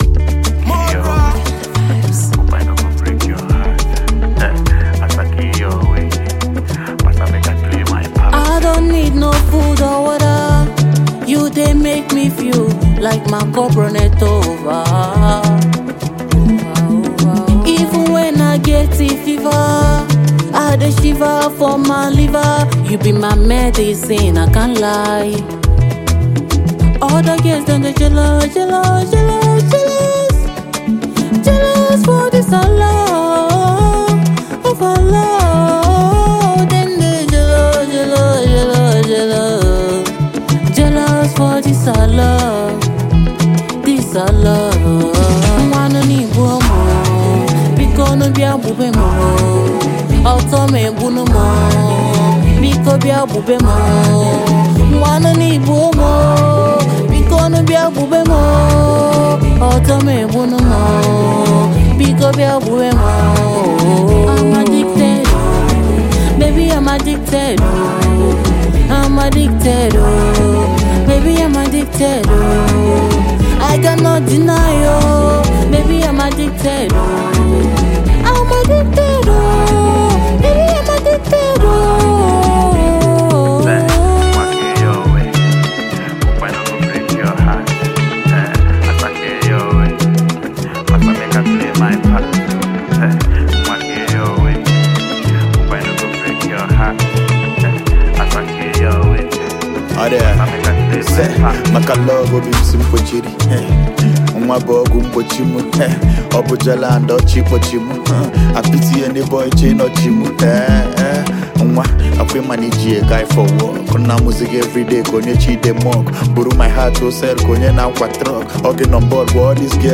Romantic Anthem
Afro-fusion
a smooth, melodious verse that elevates the track
duet